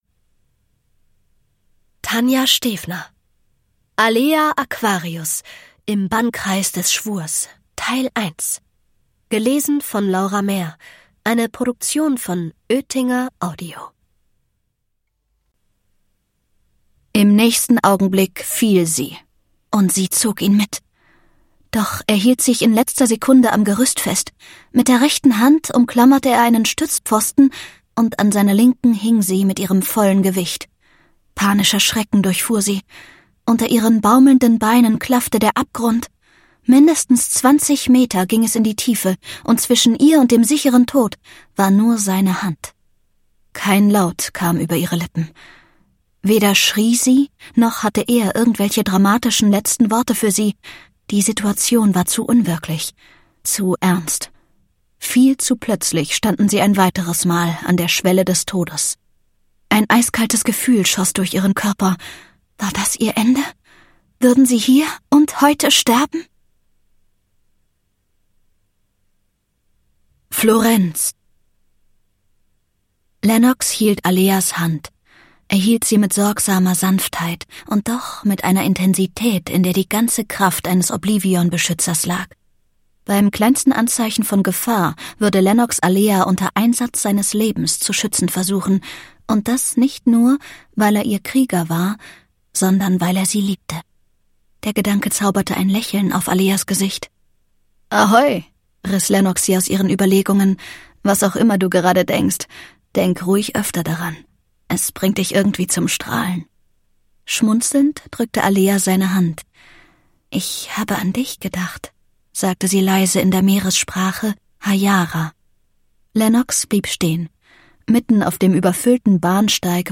Hörbuch: Alea Aquarius 7 Teil 1.